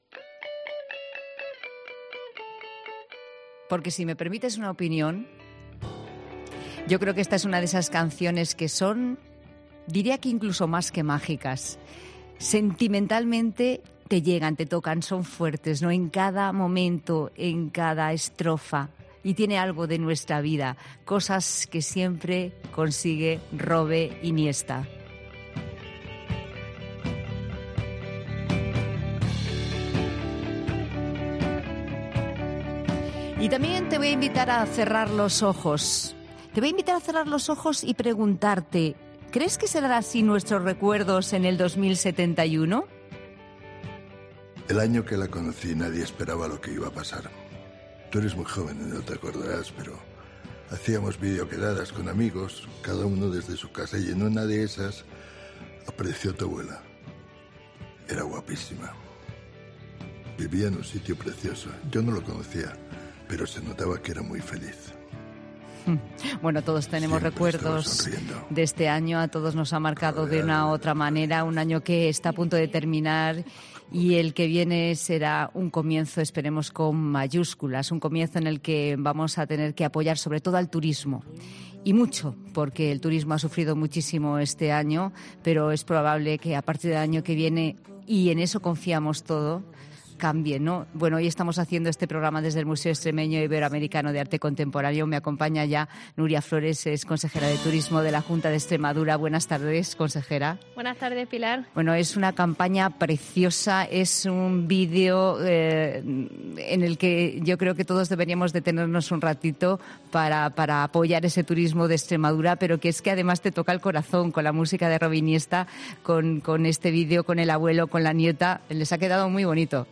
Nuria Flores,Consejera de Turismo de la Junta de Extremadura en "La Tarde de COPE"